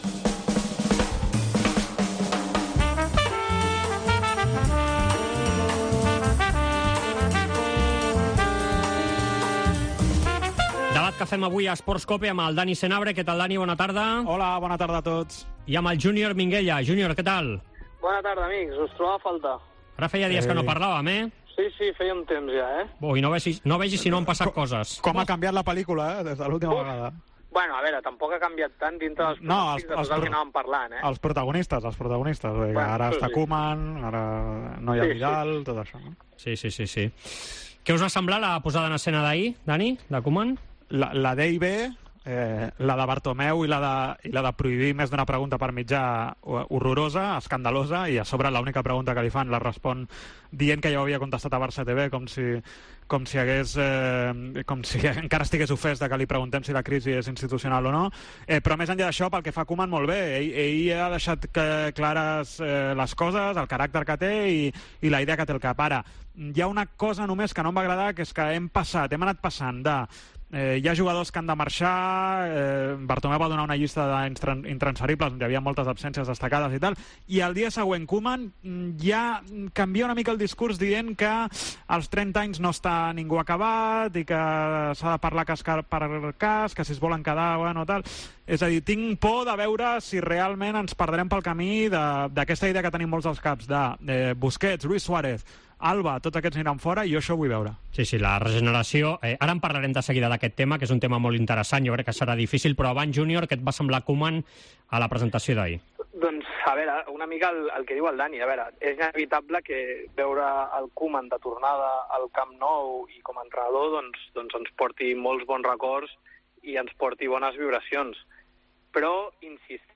Al debat Esports COPE de dijous